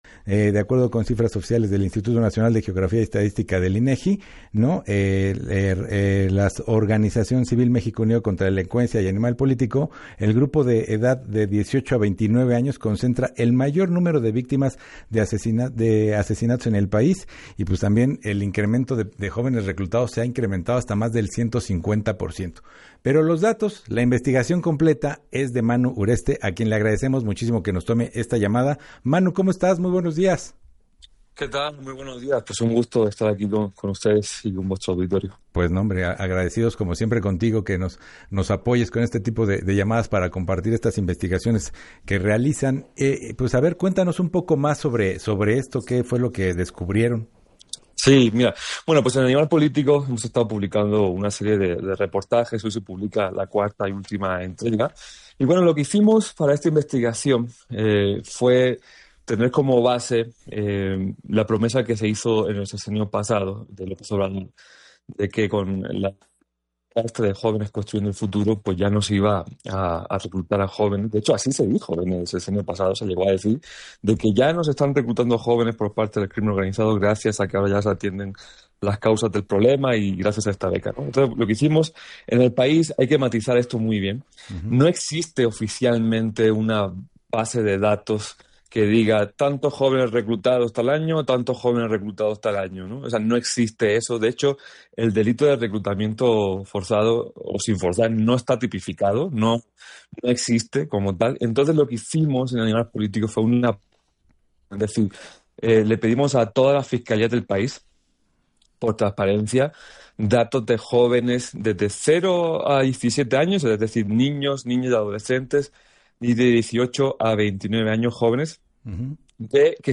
periodista de investigación